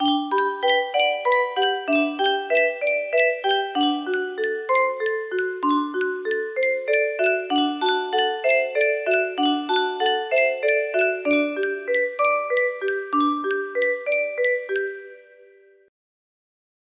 Yunsheng Music Box - The Band Played On 1732 music box melody
Full range 60